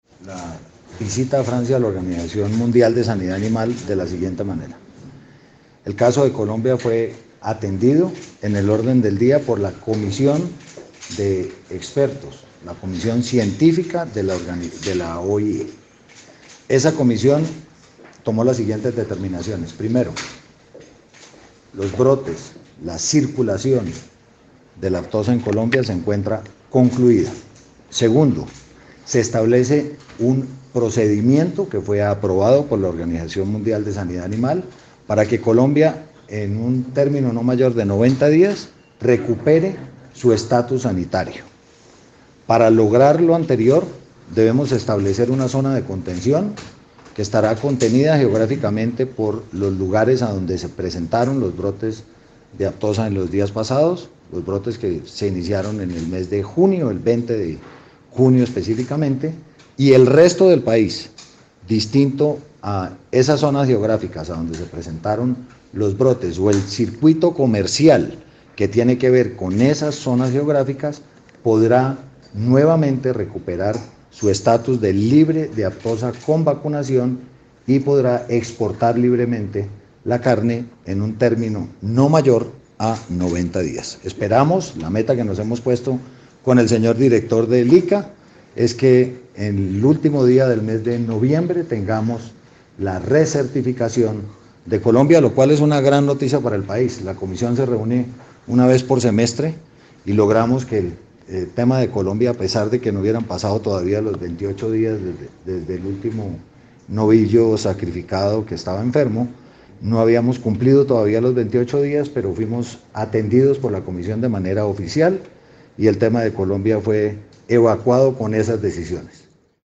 El ministro de Agricultura, Aurelio Iragorri, atiende rueda de prensa.
Declaraciones-Ministro-de-Agricultura
Declaraciones-Ministro-de-Agricultura.mp3